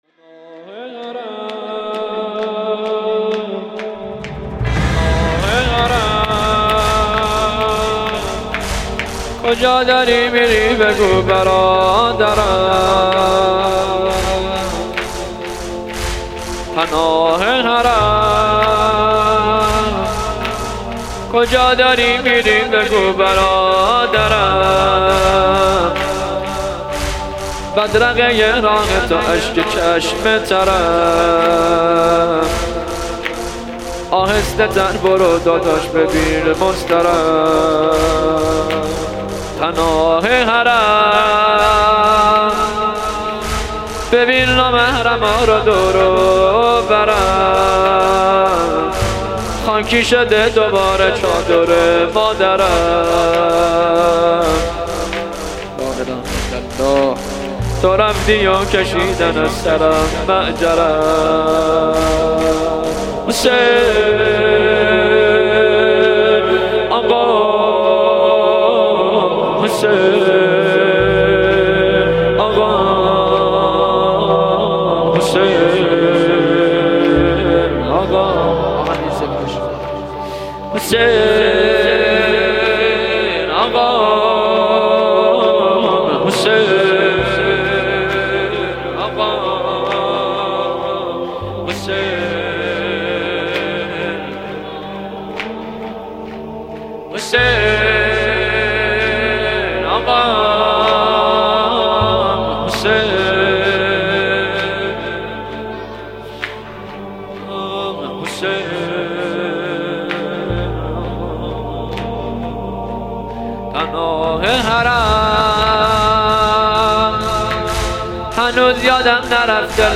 استودیویی